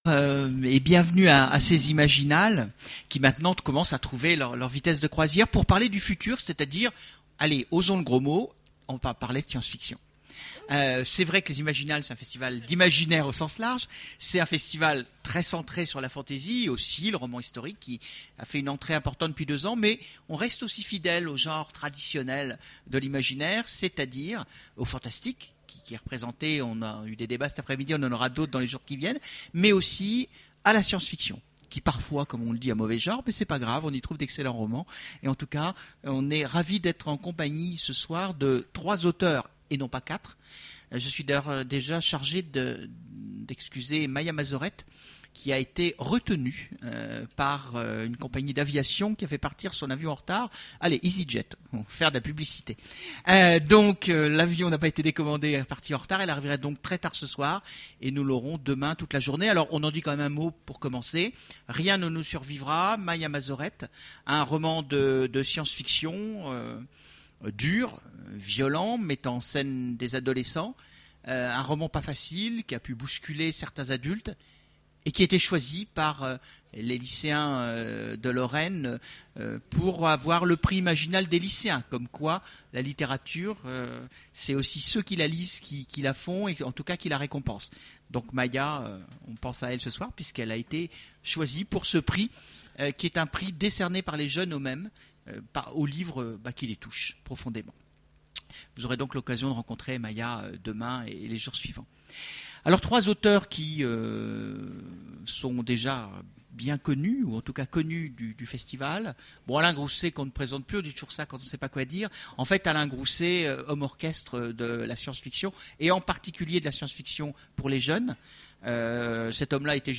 Voici l'enregistrement de la conférence Terminus le futur... aux Imaginales 2010